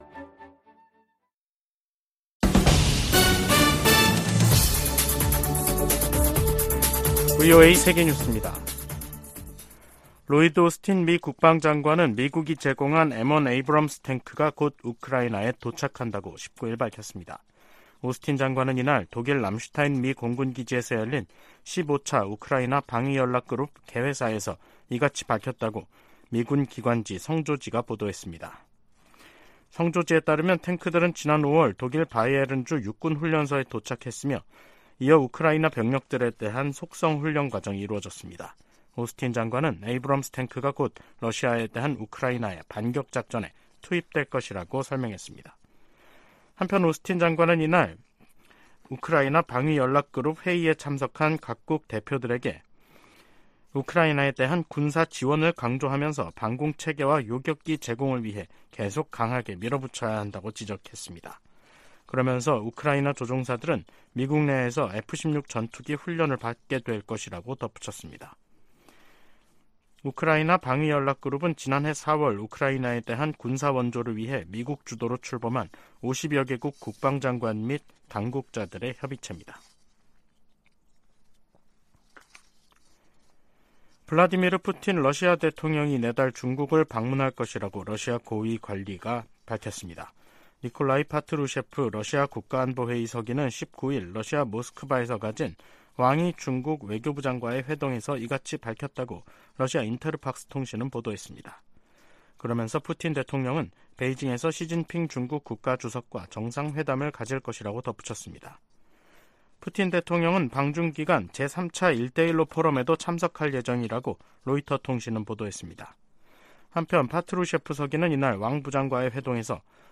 VOA 한국어 간판 뉴스 프로그램 '뉴스 투데이', 2023년 9월 19일 3부 방송입니다. 존 커비 백악관 국가안전보장회의(NSC) 전략소통조정관은 북한과 러시아가 무기거래를 할 경우 유엔 회원국과 대응책을 모색할 것이라고 밝혔습니다. 러시아가 북한 김정은 국무위원장에 무인기를 선물한 데 대해 미국 정부가 제재 부과 의지를 밝혔습니다. 북한과 중국, 러시아가 현재 3각 연대를 형성하고 있는 것은 아니라고 필립 골드버그 주한 미국 대사가 말했습니다.